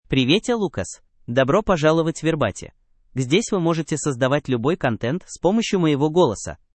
MaleRussian (Russia)
Lucas — Male Russian AI voice
Voice sample
Listen to Lucas's male Russian voice.
Lucas delivers clear pronunciation with authentic Russia Russian intonation, making your content sound professionally produced.